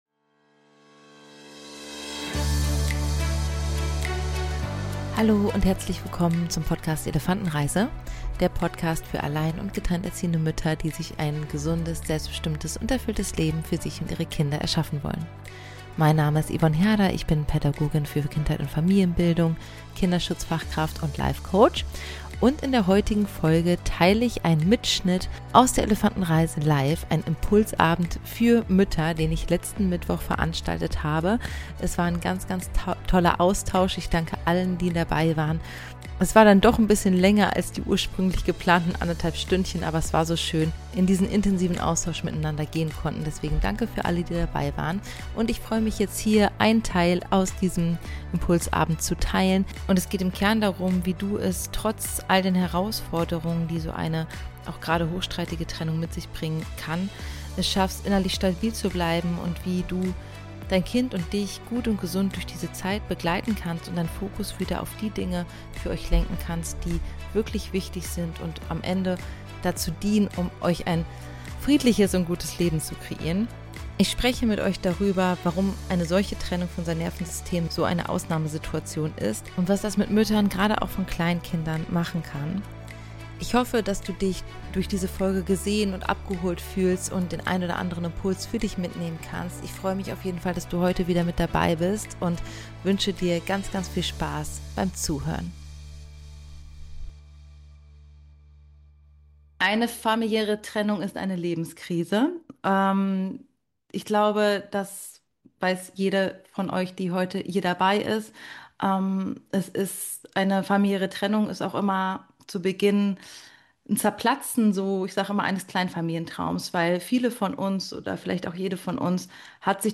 In dieser Folge hörst du einen Ausschnitt aus einem Online-Impulsabend für Mütter in herausfordernden Trennungssituationen.